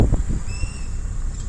Burlisto Copetón (Contopus fumigatus)
Nombre en inglés: Smoke-colored Pewee
Localidad o área protegida: Yerba Buena - Reserva Experimental Horco Molle
Condición: Silvestre
Certeza: Vocalización Grabada
burlisto-copeton-mp3.mp3